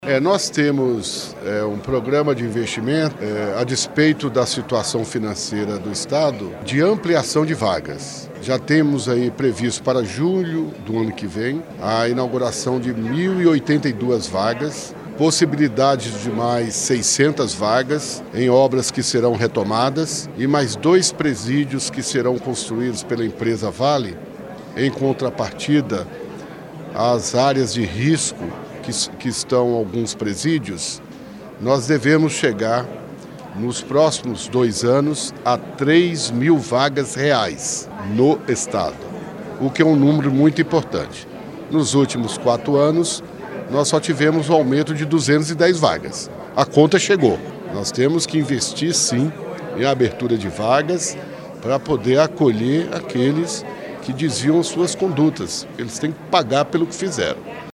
O anúncio de ampliação de vagas em presídios foi feito nesta quarta-feira, 6, durante coletiva de imprensa, com o secretário de Justiça e Segurança Pública, general Mario Araujo.
secretário de Justiça e Segurança Pública, general Mario Araujo